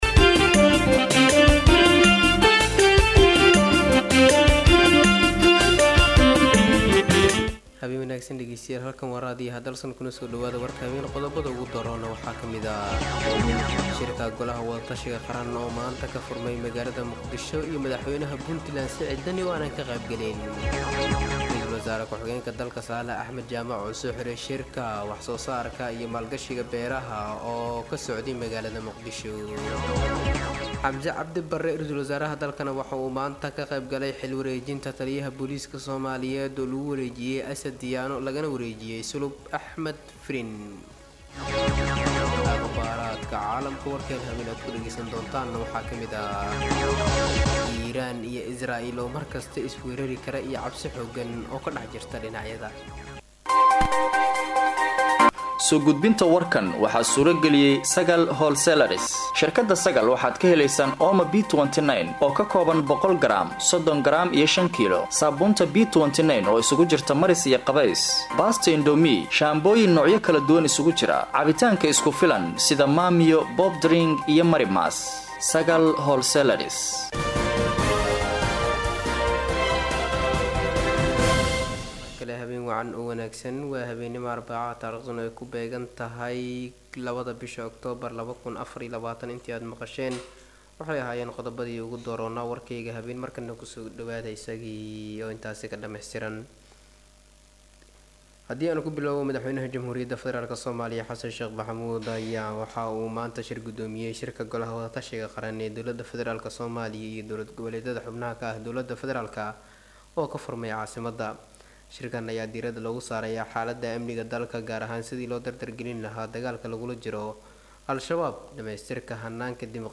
Dhageyso:- Warka Habeen Ee Radio Dalsan 02/10/2024